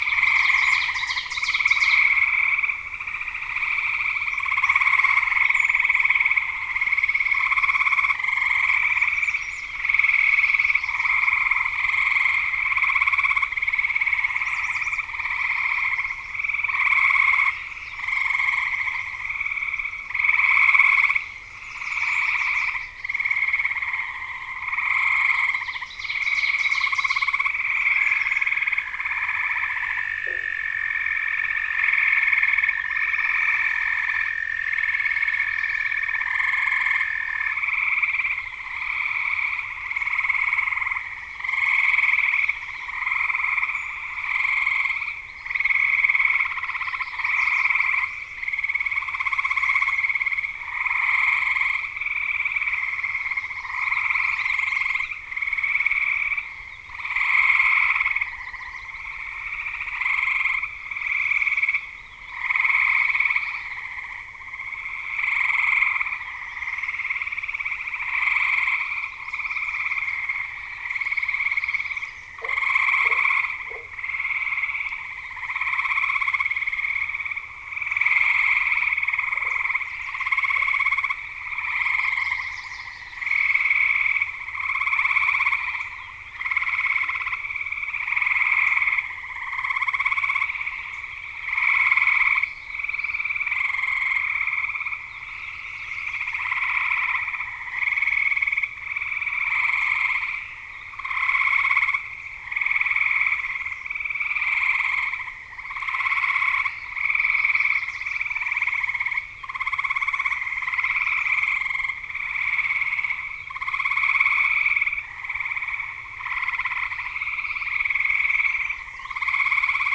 Fig. 12d. Soundscapes produced from acoustic data we collected with digital sound recorders at site SC4DBI2 in the St. Croix National Scenic Riverway in 2008 (left) and 2009 (right).
Late night sounds at this site in the St. Croix National Scenic Riverway on May 31, 2008 (site contained standing water and amphibians were calling).